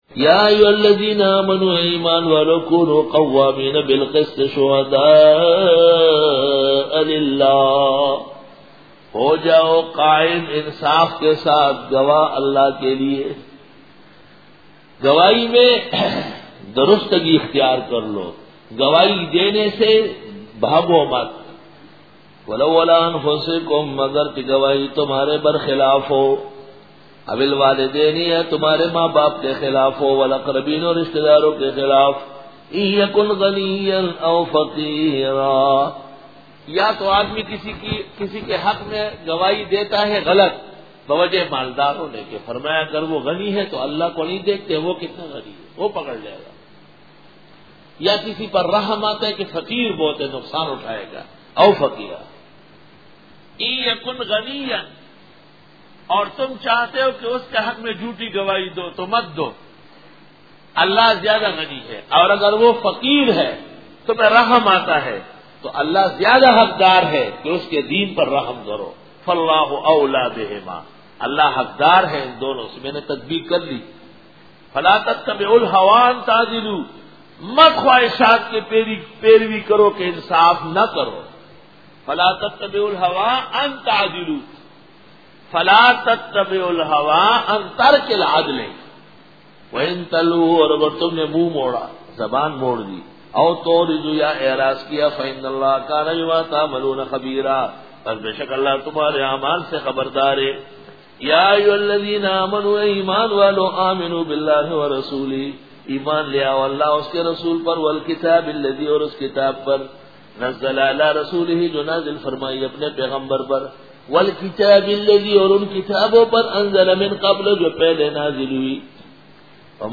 Dora-e-Tafseer